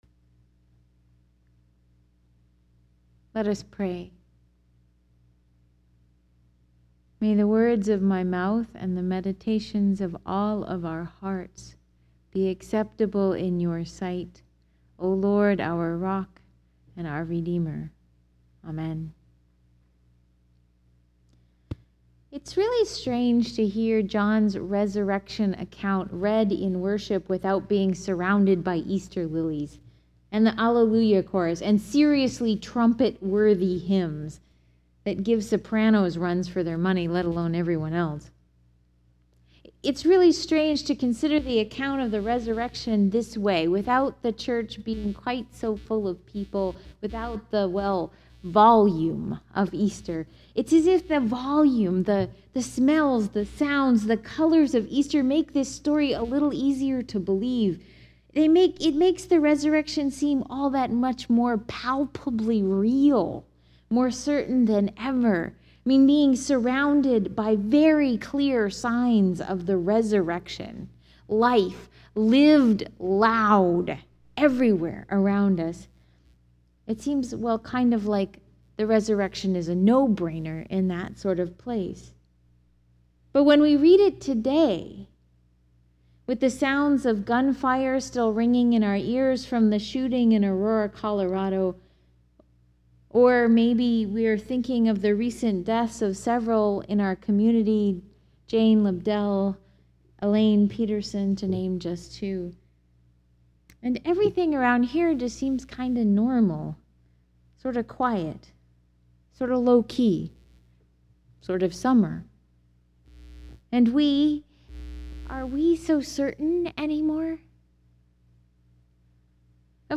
feastofstmarymagdalene-2012.mp3